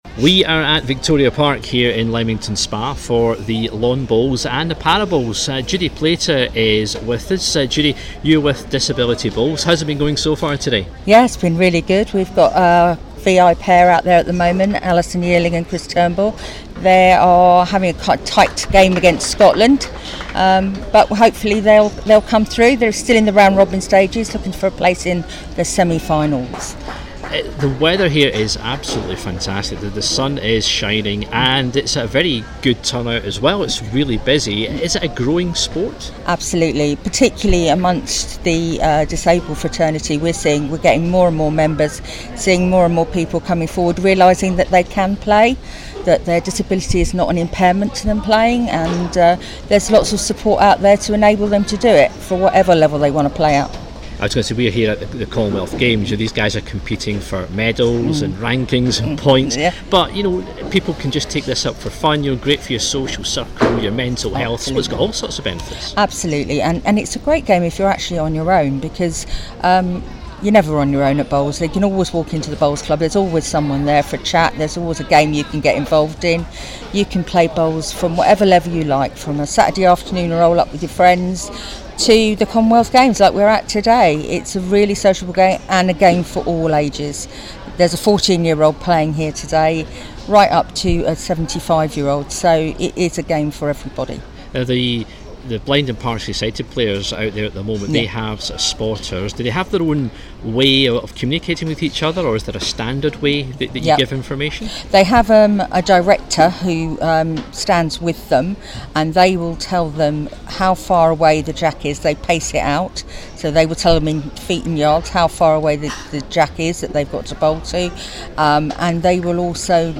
is at the Para Bowls heats for the 2022 Commonwealth Games